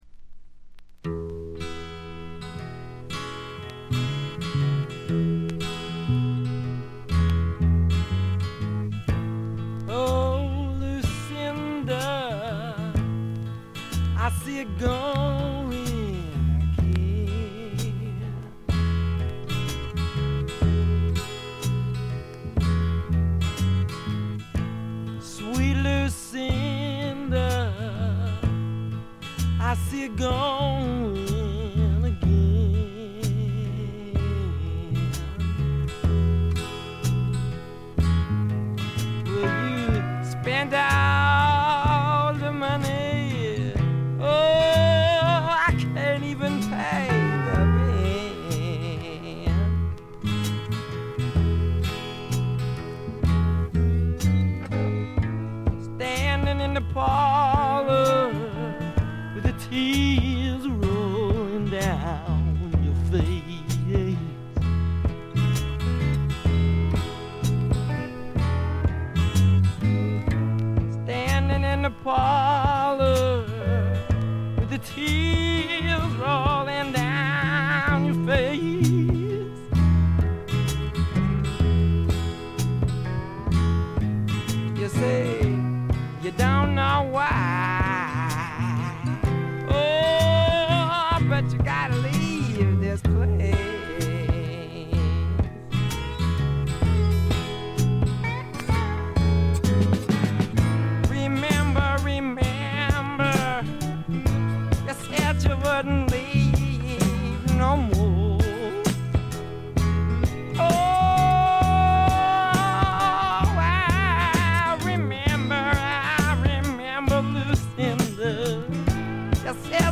軽微なチリプチ程度（B1冒頭だけちょっと目立つ）。
恐ろしい完成度を誇るメンフィス産の名作にしてスワンプロックを代表する名盤。
まさしくスワンプロックの理想郷ですね。どこからどこまで、どこを切っても完璧なメンフィス・サウンドです。
試聴曲は現品からの取り込み音源です。
Vocals, Acoustic Guitar, Piano, Violin
BACKGROUND VOCALS